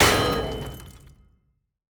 Damaged Sound.wav